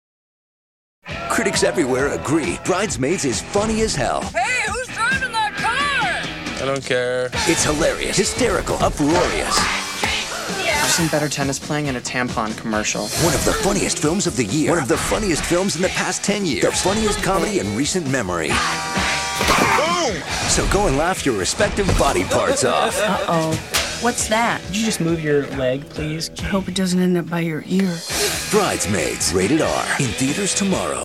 TV Spots